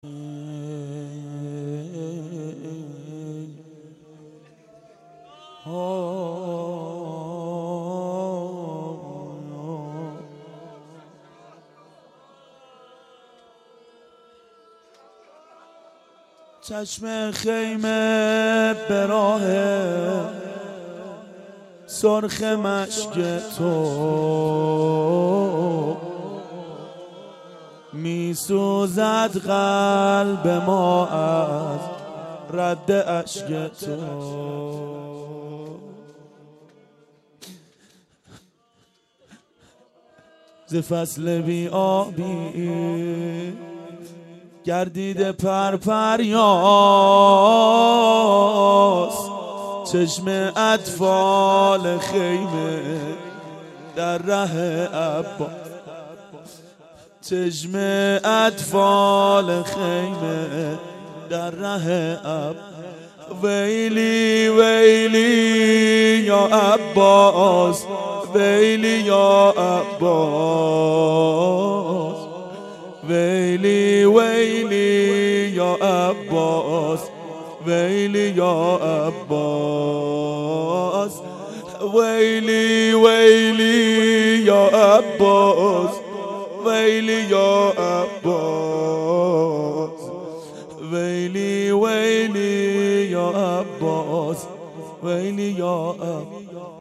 شب تاسوعا 92 هیأت عاشقان اباالفضل علیه السلام منارجنبان